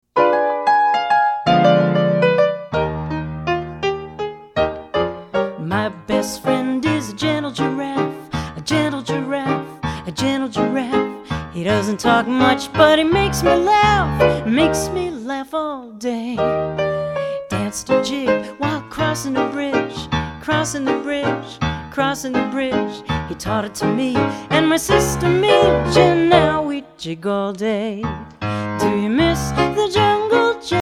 Vocal and